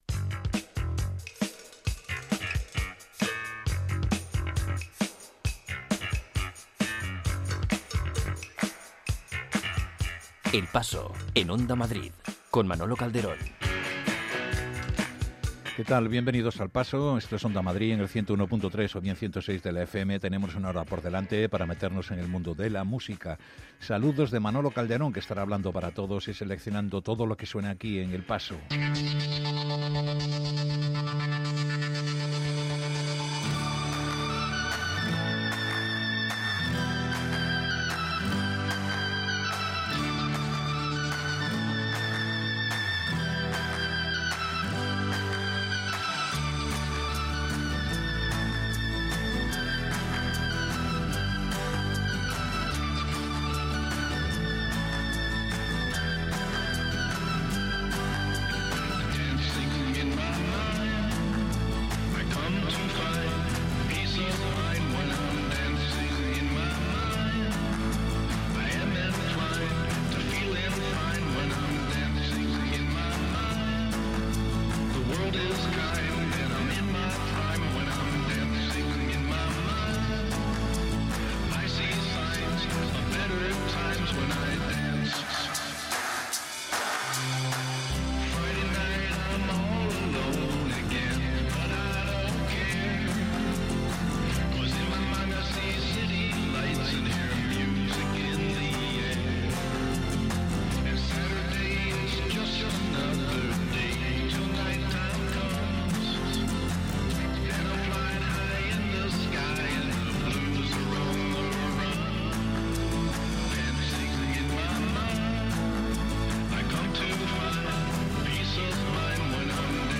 No hay década mala en el repaso musical que hace El Paso, desde el primigenio rock de los años 50 hasta el blues contemporáneo, pasando por el beat, power pop, surf, punk, rock o música negra.